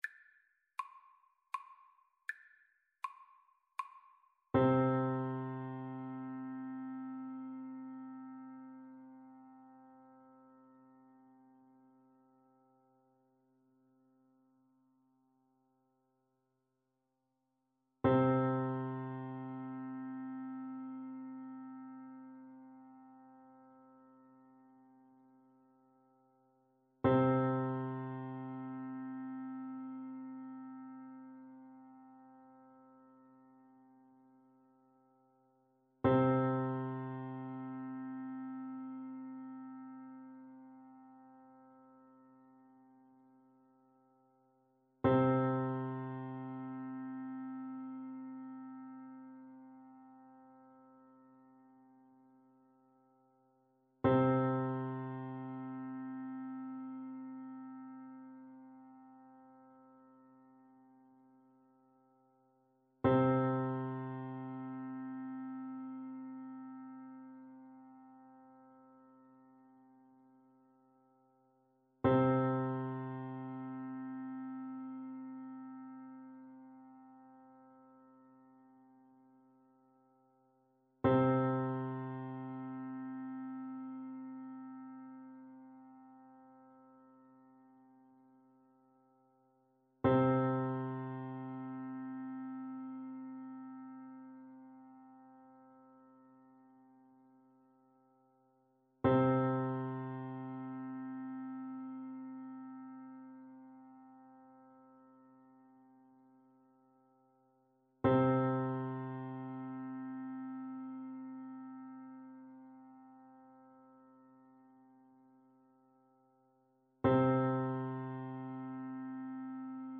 Traditional Trad. Sleep Dearie Sleep (as used in the funeral of Queen Elizabeth II) Flute version
Flute
F major (Sounding Pitch) (View more F major Music for Flute )
3/4 (View more 3/4 Music)
Slowly and freely, in the manner of bagpipes =80
Traditional (View more Traditional Flute Music)